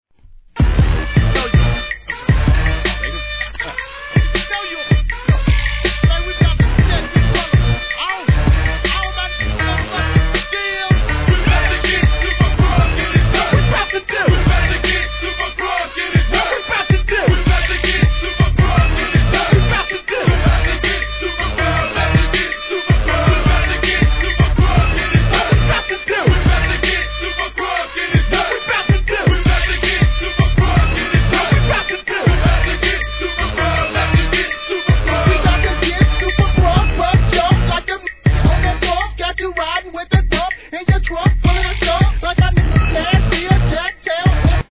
G-RAP/WEST COAST/SOUTH
タイトル通りのサイレン音鳴り響くCRUNKサウンドで